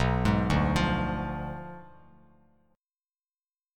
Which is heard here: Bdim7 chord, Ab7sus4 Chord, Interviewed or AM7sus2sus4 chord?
Bdim7 chord